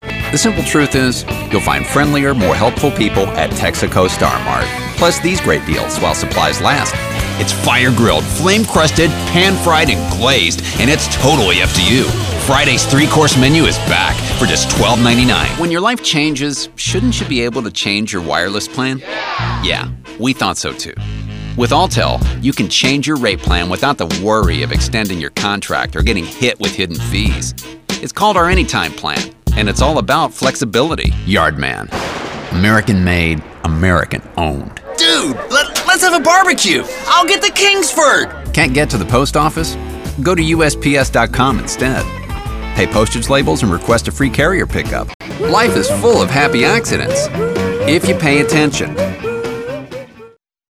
commercial demo prototype
Current Demo